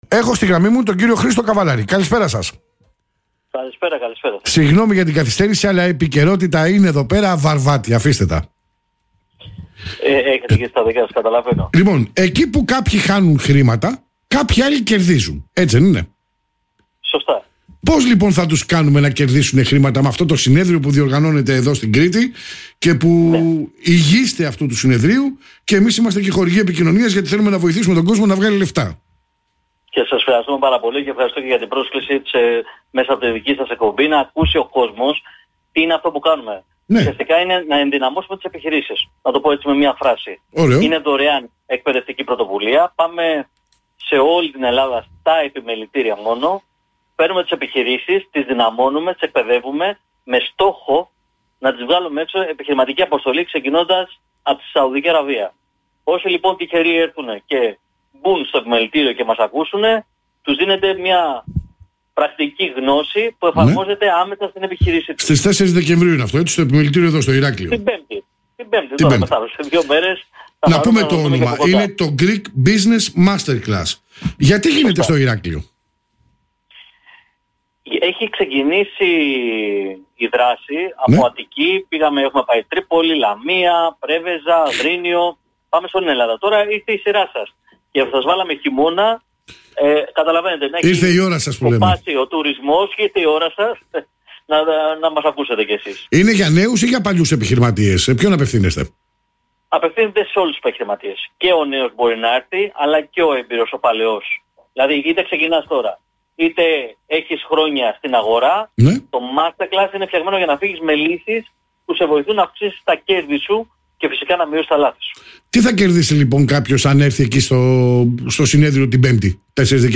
Ερωτήσεις & Απαντήσεις